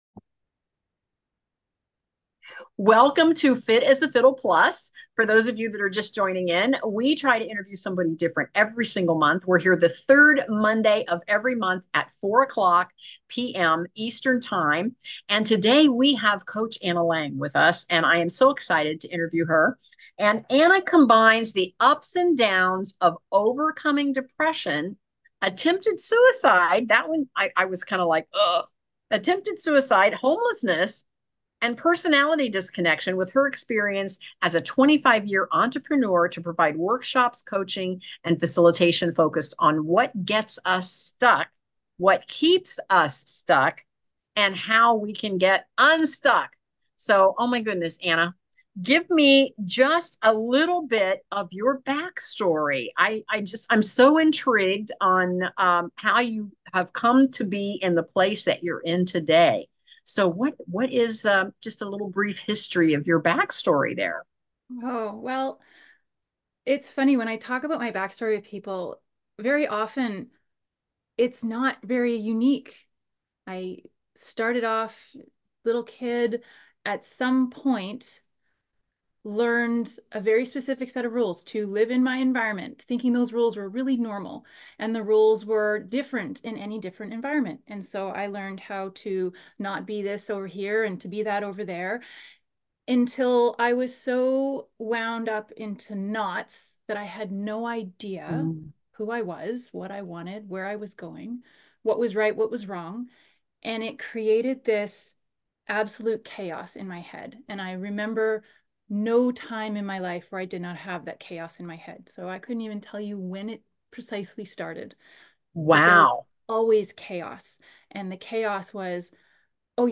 Missed last Monday's podcast? Don't worry, we've got you covered with an inspiring REPLAY!